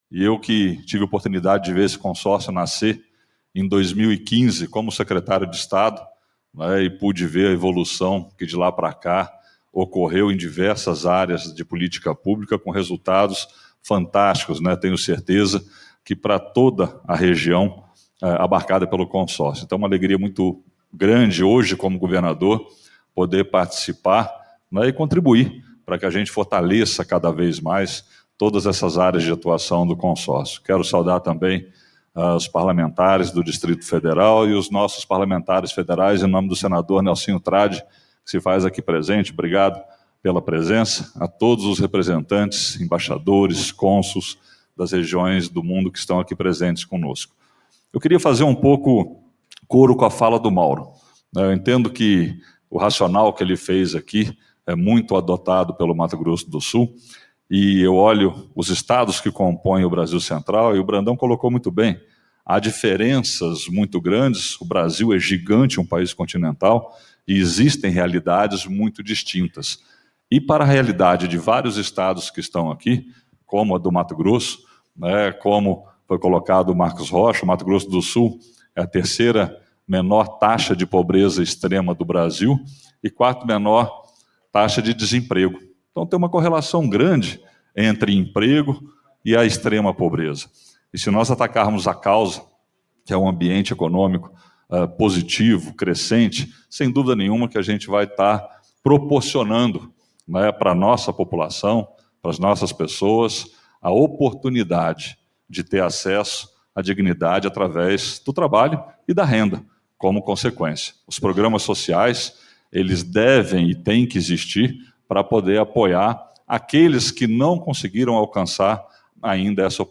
A declaração foi feita durante reunião do Fórum dos Governadores do Brasil Central.
FALA-RIEDEL-CONSORCIO-GOVERNADORES-1.mp3